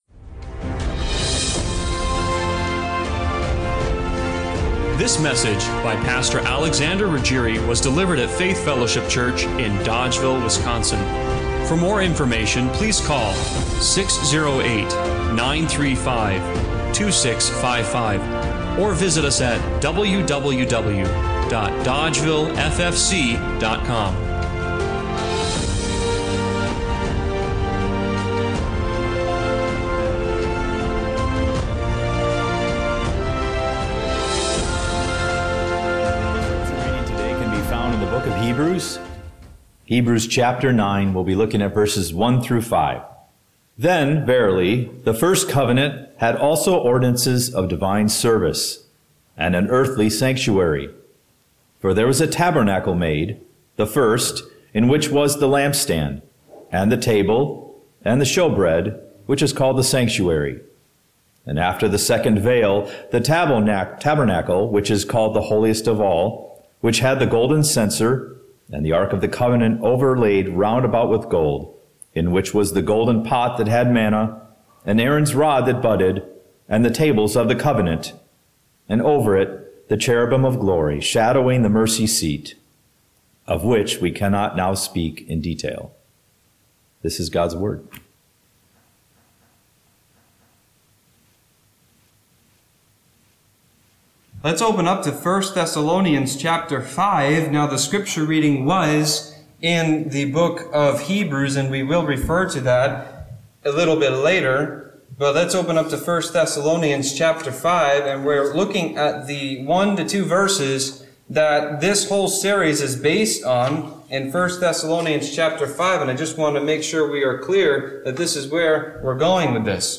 1 Thessalonians 5:23 Service Type: Sunday Morning Worship What if the real you isn’t just skin deep—but soul deep